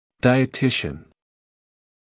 [ˌdaɪə’tɪʃn][ˌдайэ’тишн]диетолог